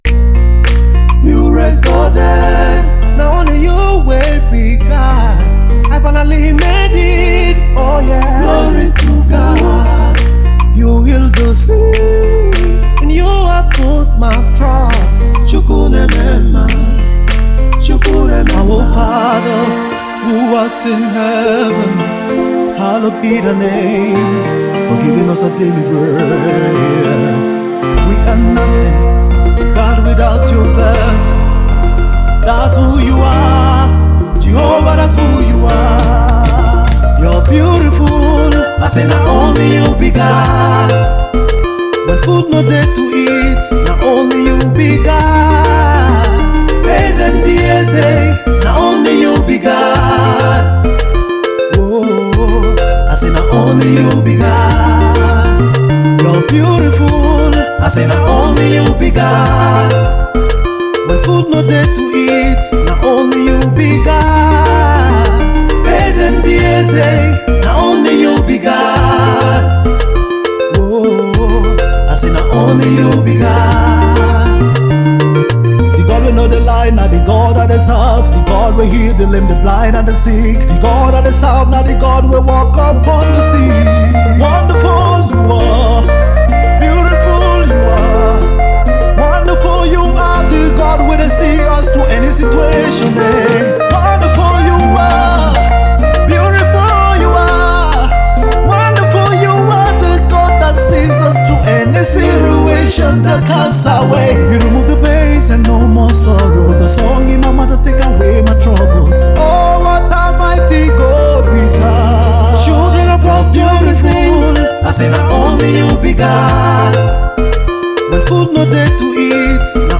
soul lifting, breathtaking gospel worship tune